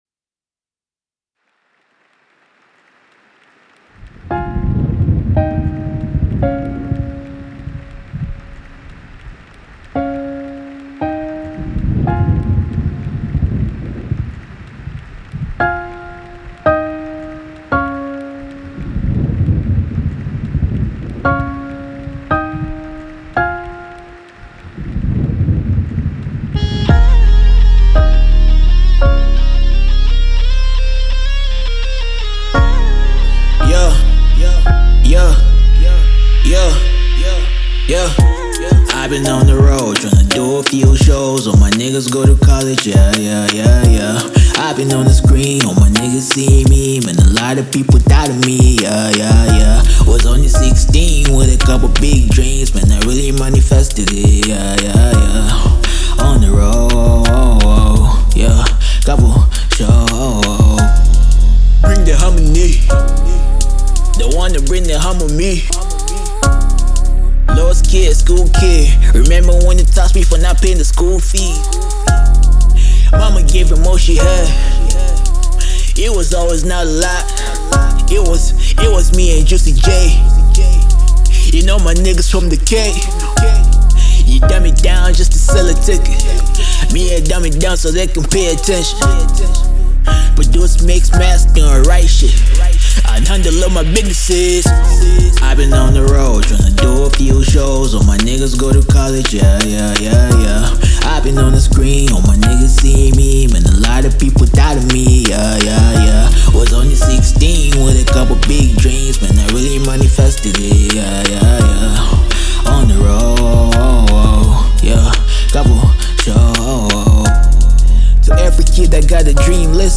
Hiphop
Recorded in a basement somewhere in Seattle